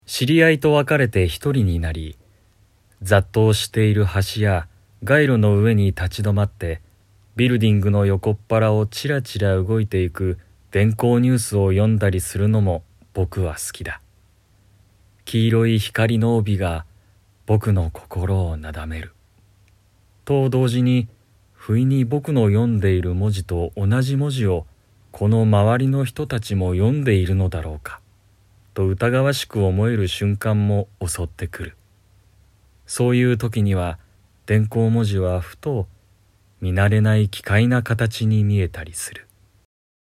朗読
ボイスサンプル